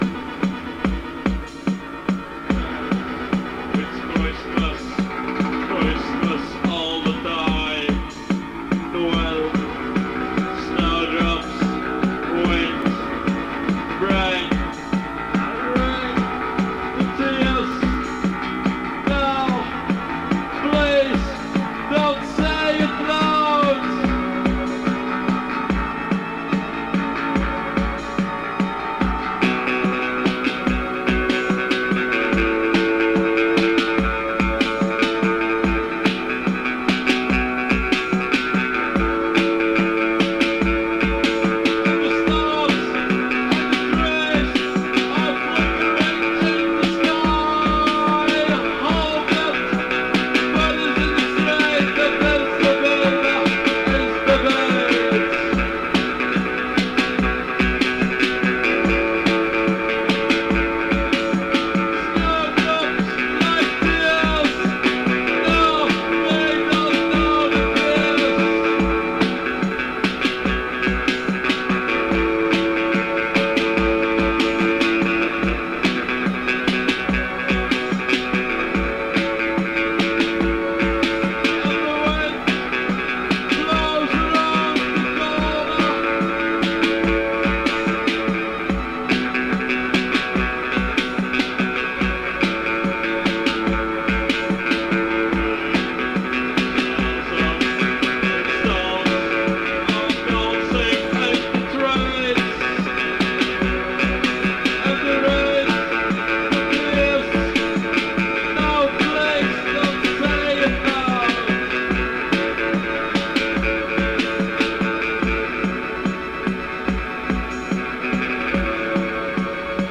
an industrial and electronic variant of the sound
Electro Wave Indie